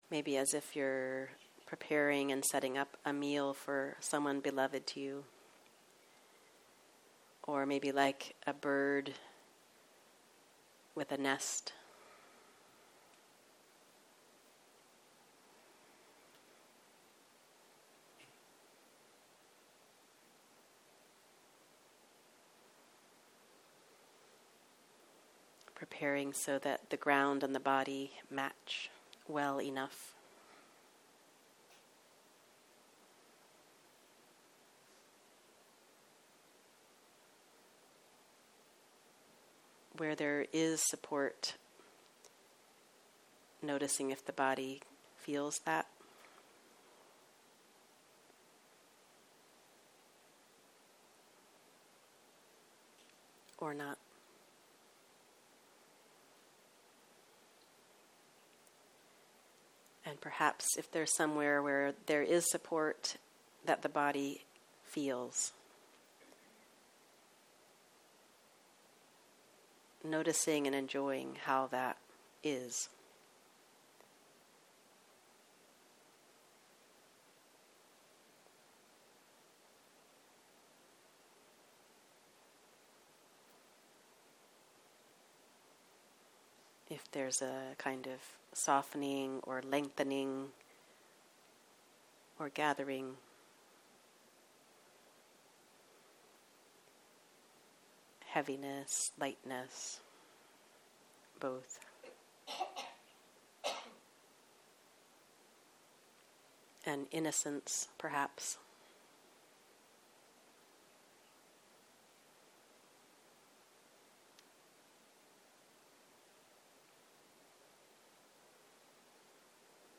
בוקר - מדיטציה מונחית והנחיות למדיטציות. Working with emotions
סוג ההקלטה: שיחת הנחיות למדיטציה